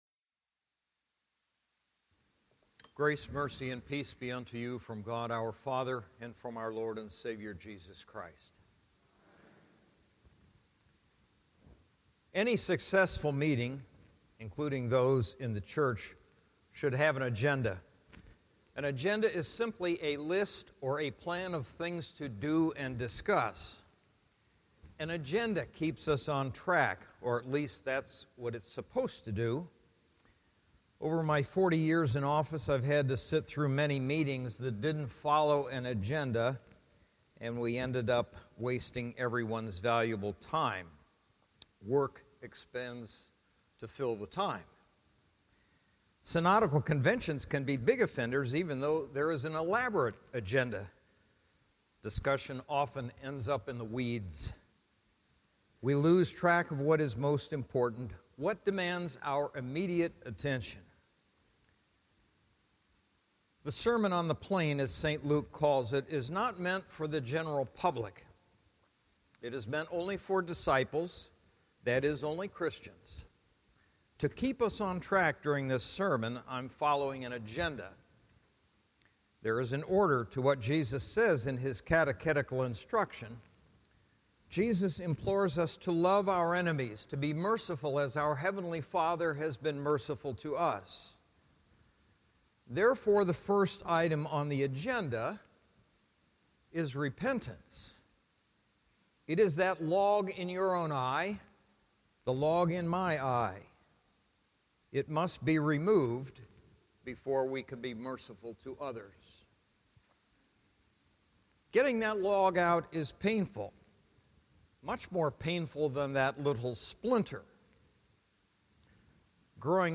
Fourth Sunday after Trinity
Sermons